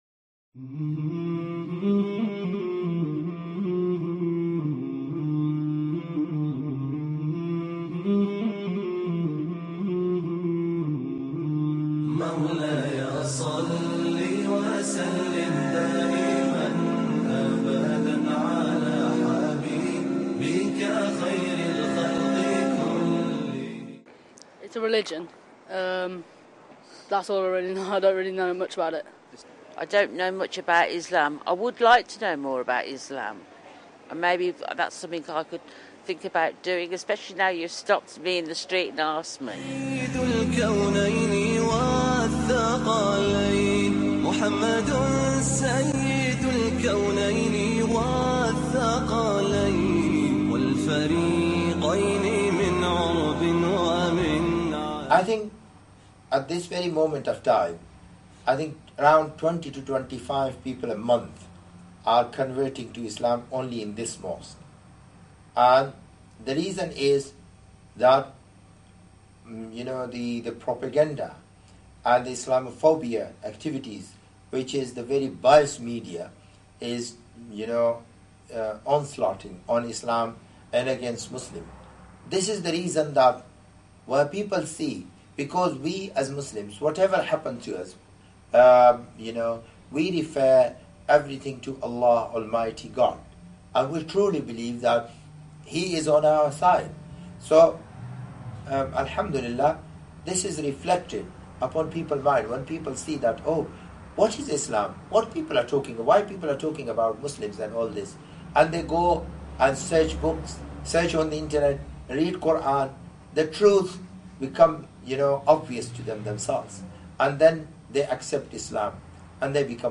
This episode features a powerful documentary about the rapid growth of Islam in the West, particularly in the United Kingdom. Featuring testimonies from new Muslims of diverse backgrounds — including former Christians, former gang members, and everyday people searching for truth — the film demonstrates that Islam continues to attract sincere seekers despite (and often because of) the negative media coverage and Islamophobia directed against it.